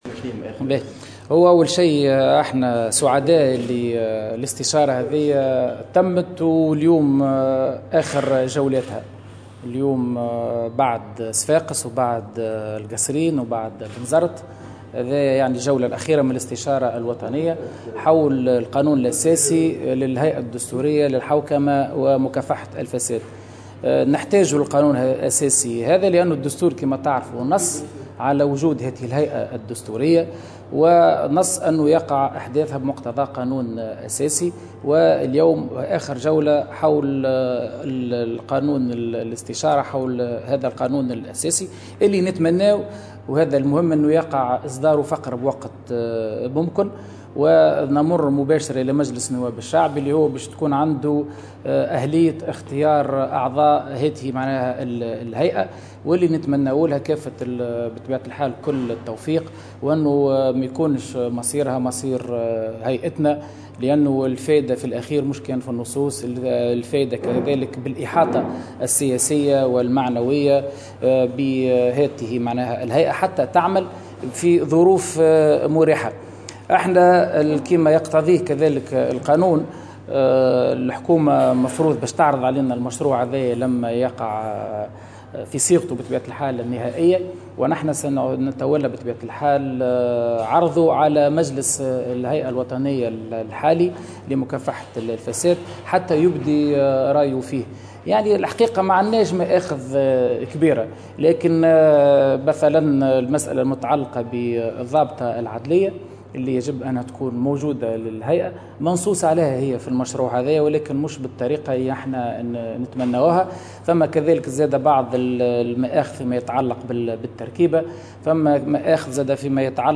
أكد رئيس الهيئة الوطنية لمكافحة الفساد شوقي الطبيب في تصريح لمراسلة الجوهرة "اف ام" اليوم الجمعة 27 ماي 2016 أن الاستشارة الوطنية حول القانون الأساسي للهيئة الدستورية للحوكمة ومقاومة الفساد بلغت اليوم اخر جولاتها بعد صفاقس والقصرين وبنزرت .